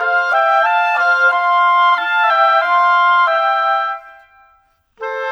Rock-Pop 22 Clarinet _ Oboe 01.wav